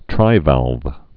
(trīvălv)